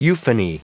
Prononciation du mot euphony en anglais (fichier audio)
Prononciation du mot : euphony